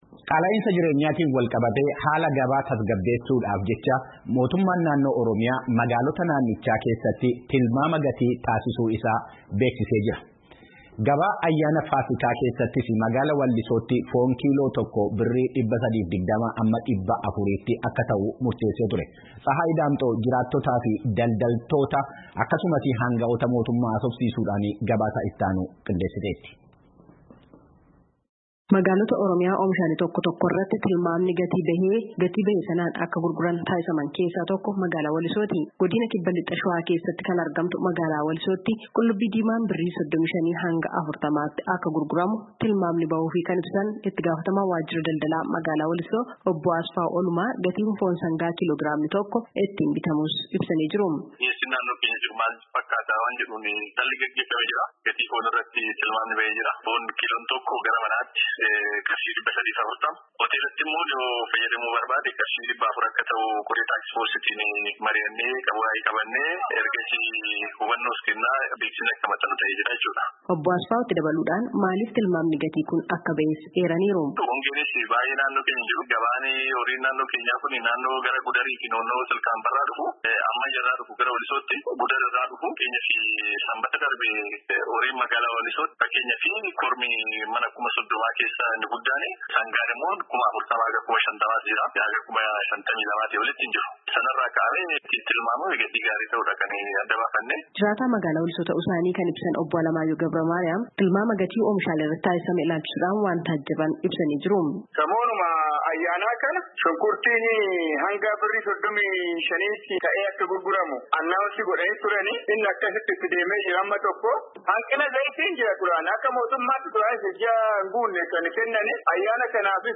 jiraattota, daldaltoota fi aanga’ootaa mootummaa dubbisuun gabaasaa qindeessite qabdi.